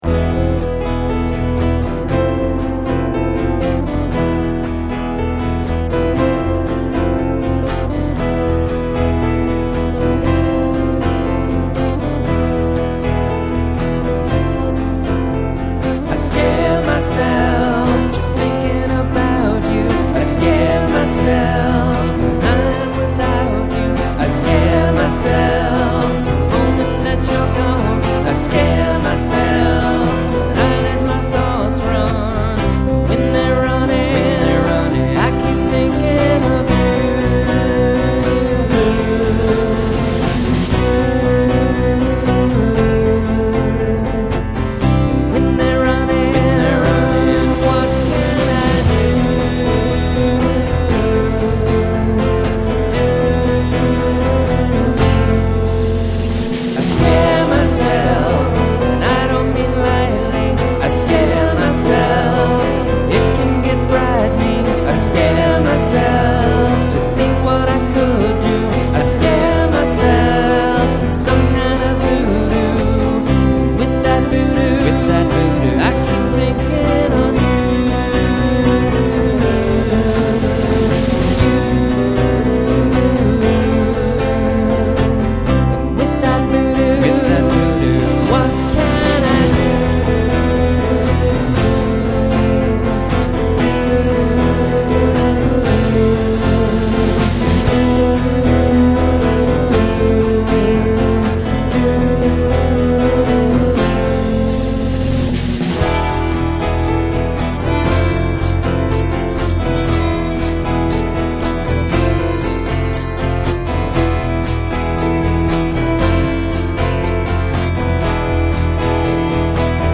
his music could be described as swing bluegrass rock.